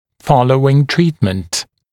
[‘fɔləuɪŋ ‘triːtmənt][‘фолоуин ‘три:тмэнт]после завершения лечения